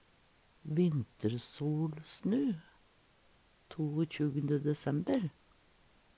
DIALEKTORD PÅ NORMERT NORSK vintersolsnu, vintersolværv 21. desember vintersolsnu, vintersolkverv 21. desember Hør på dette ordet Ordklasse: Særnamn (andre) Kategori: Tida (dagen, året, merkedagar) Attende til søk